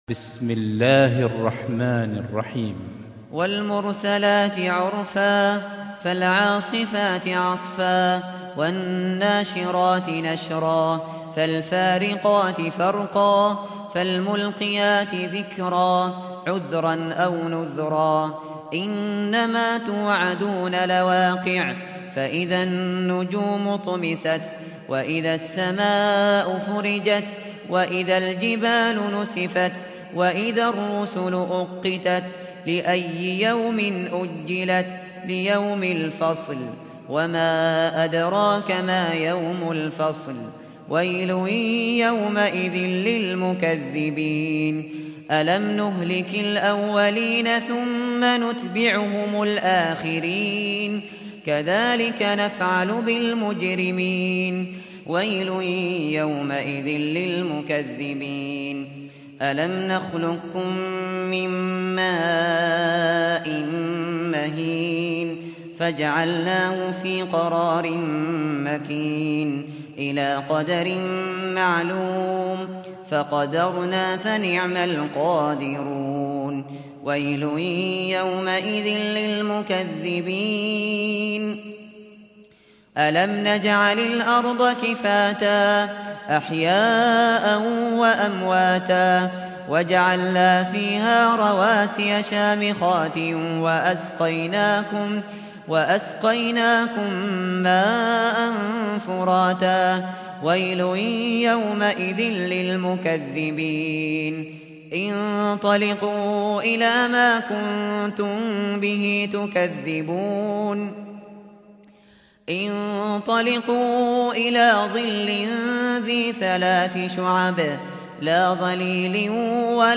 سُورَةُ المُرۡسَلَاتِ بصوت الشيخ ابو بكر الشاطري